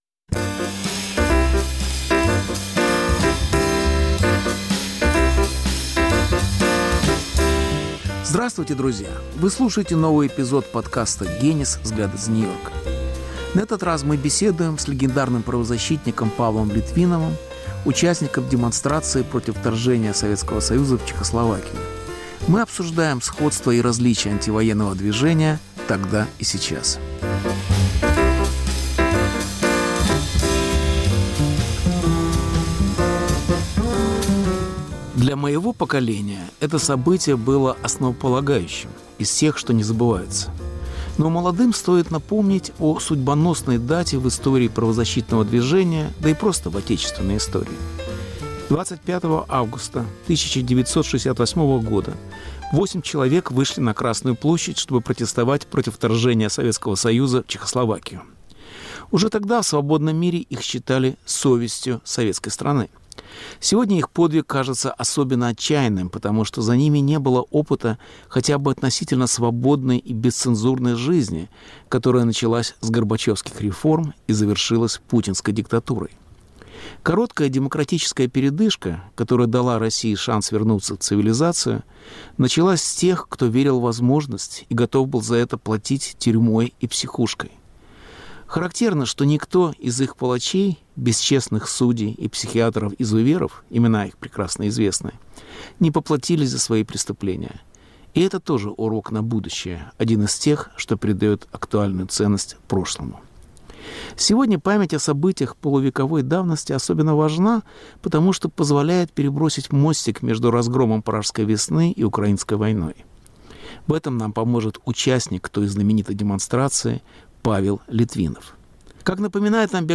Беседа с правозащитником, участником демонстрации 25 августа 1968 года против разгрома Пражской весны